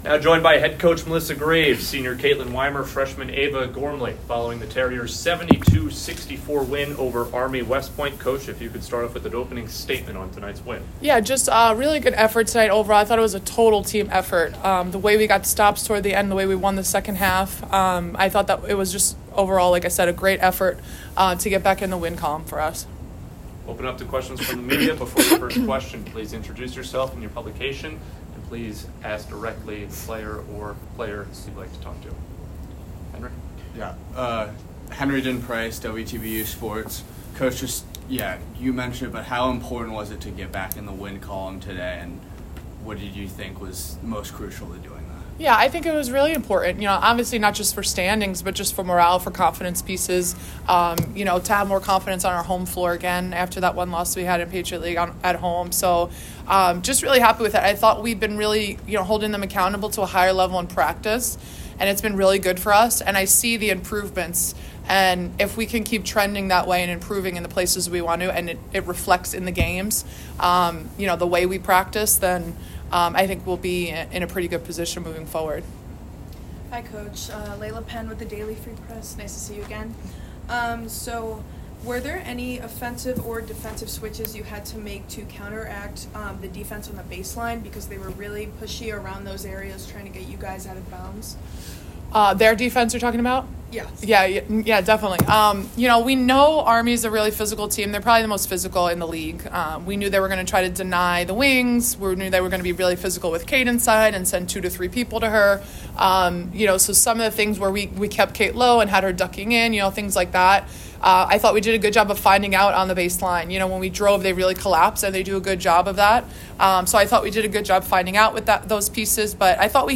Women's Basketball / Army West Point Postgame Press Conference